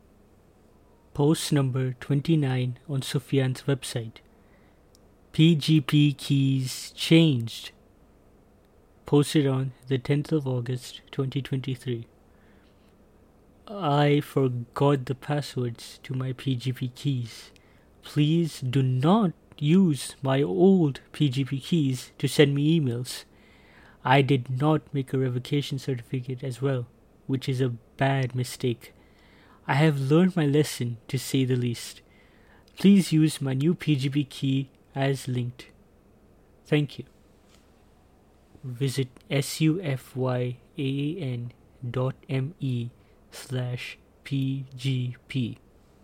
voiceover.mp3